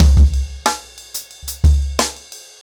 InDaHouse-90BPM.21.wav